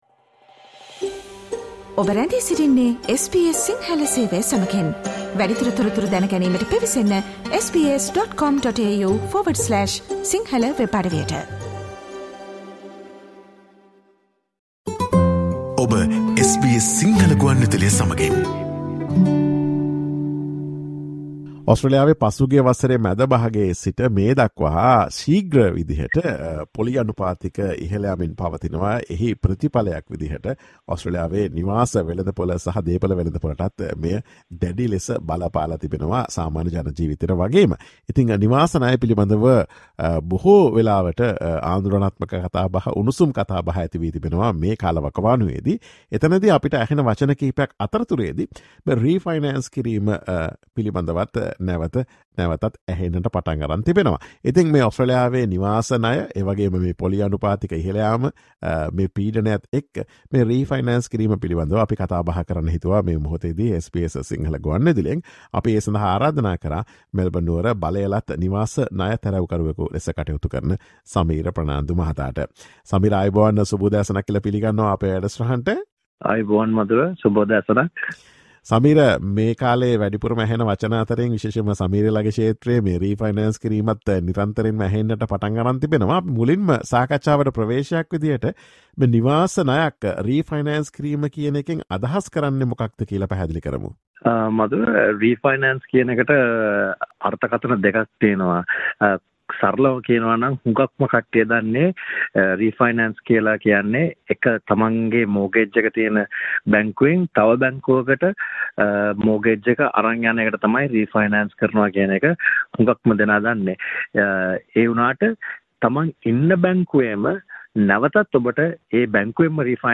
Listen to SBS Sinhala Radio's discussion on how to refinance a mortgage in Australia and what are the requirements.